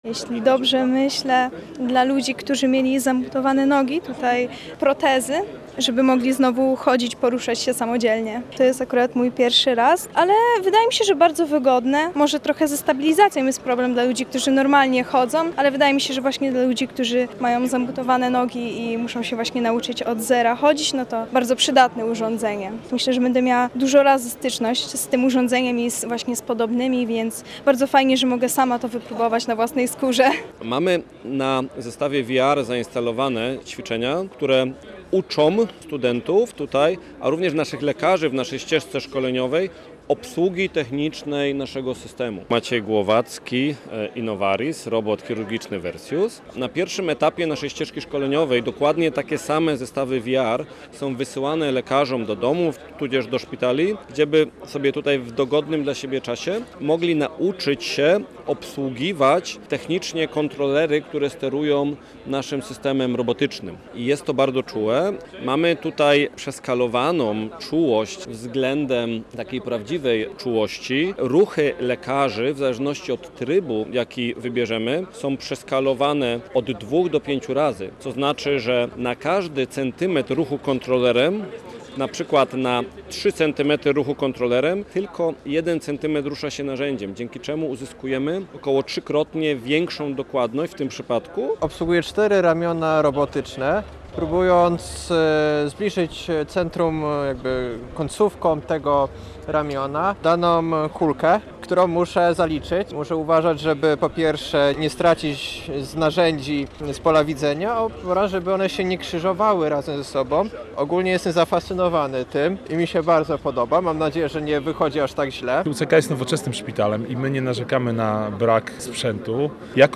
Z uczestnikami wydarzenia rozmawiał nasz reporter.